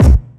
GS Phat Kicks 003.wav